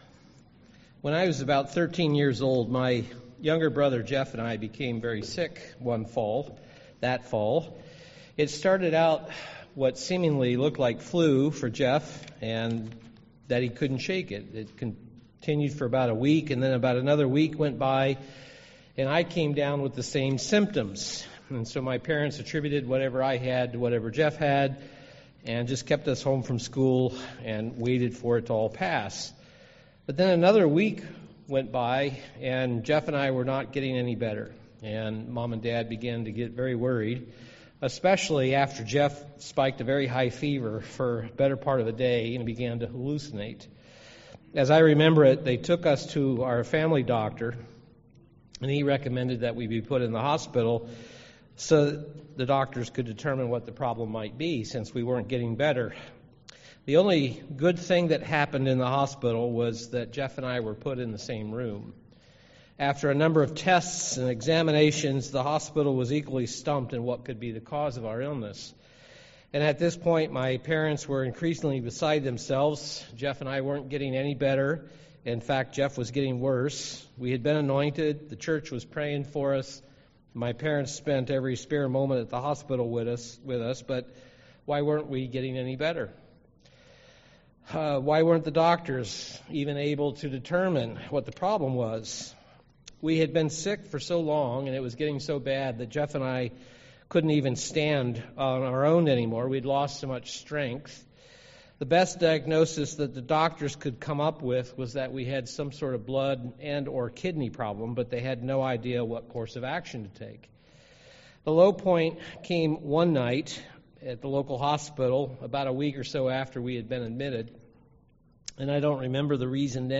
Given in Milwaukee, WI
UCG Sermon Healing divine divine healing illness sickness Disease sin Studying the bible?